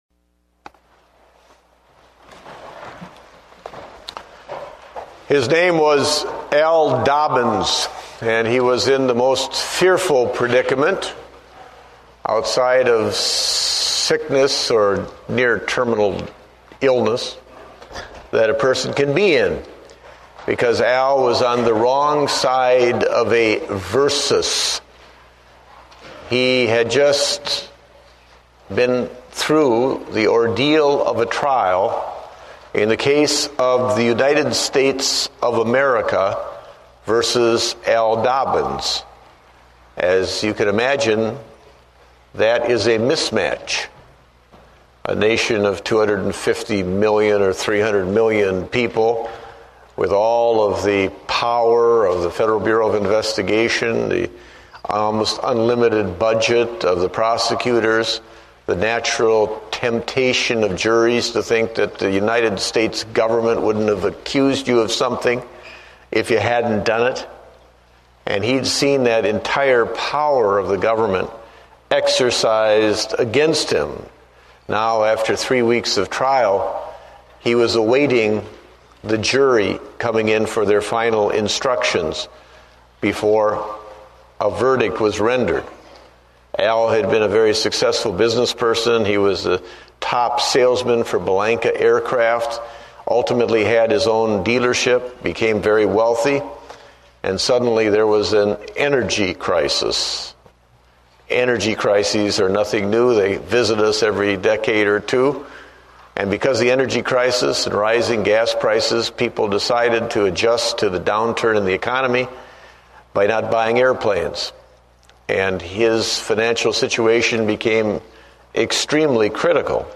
Date: January 4, 2009 (Morning Service)